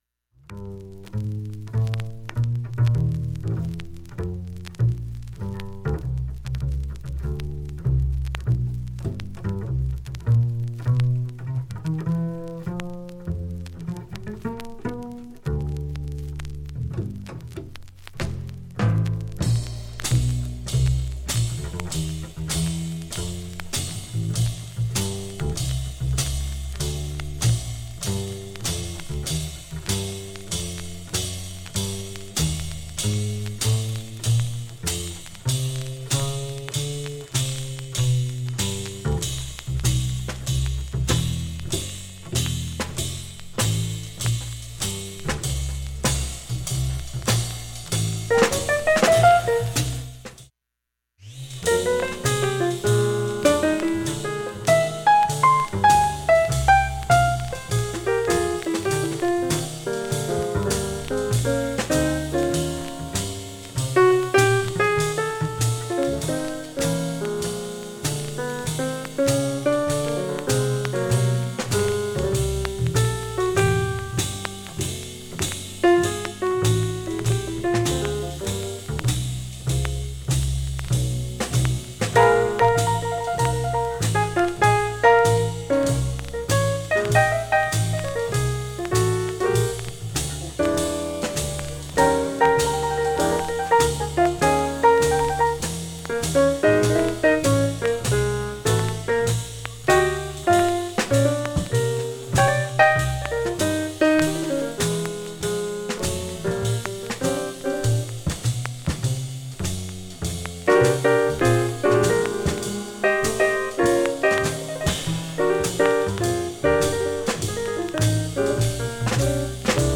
チリもかなり少なく普通に聴けます
音質良好全曲試聴済み。
静かな部なので聴こえる小さいもの
15回までのかすかなプツが１箇所
単発のかすかなプツが９箇所